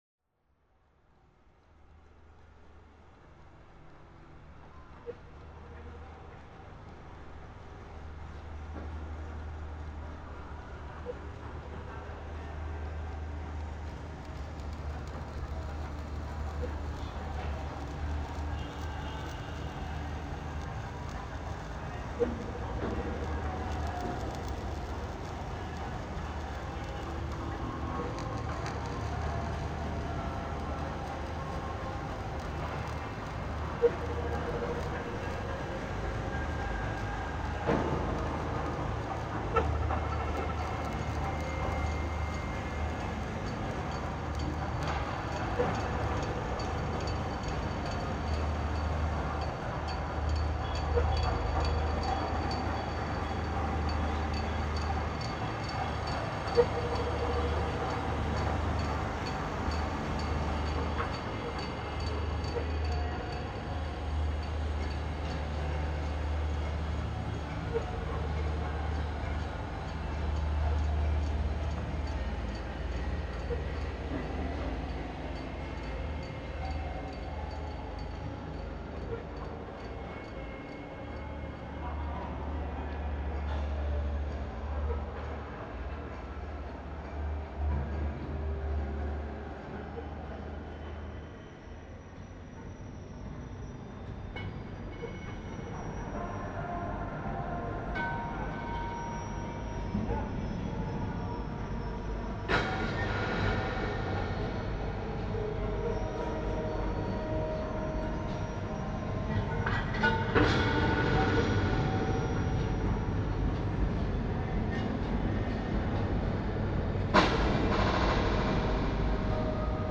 Industrial drone